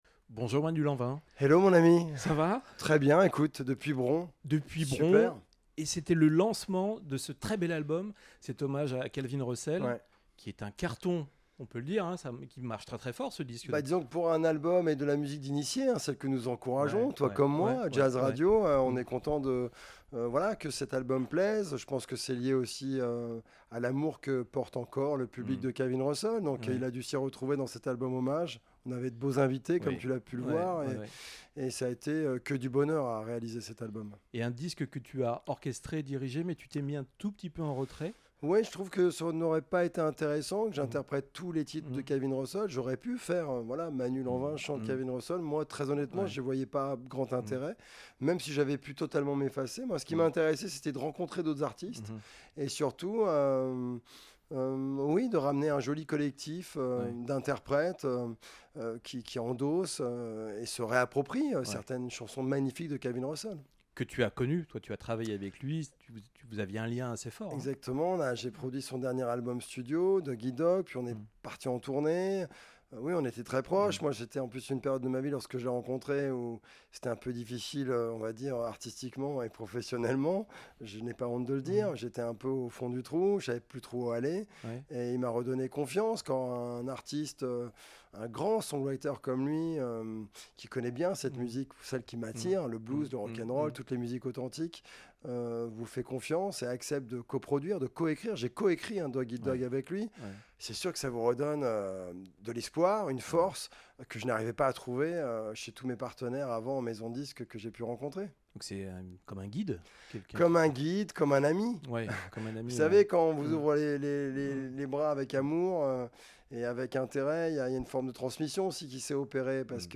Interview Jazz Radio